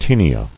[tae·ni·a]